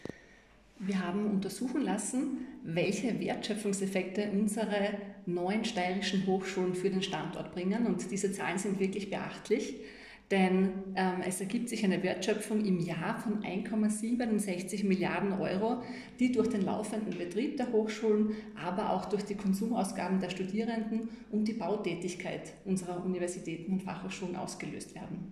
LR Barbara Eibinger-Miedl im O-Ton: